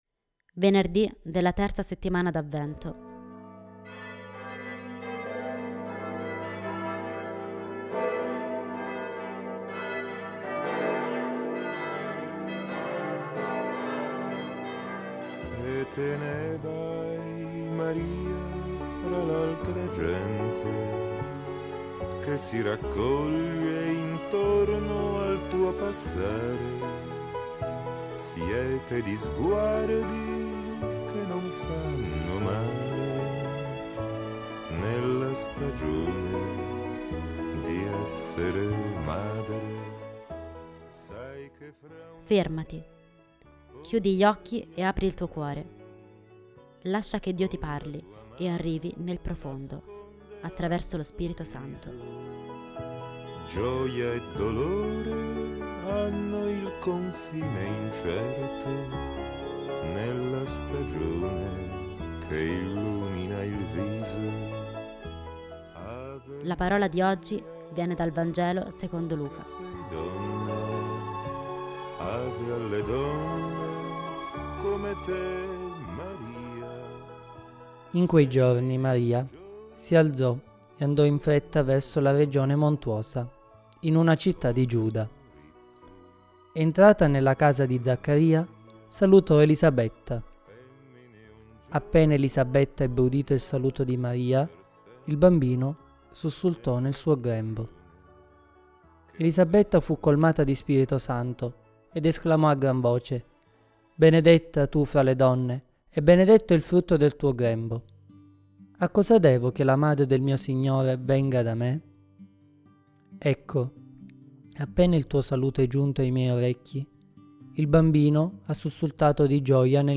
Commento
Musica di Fabrizio de André: Ave Maria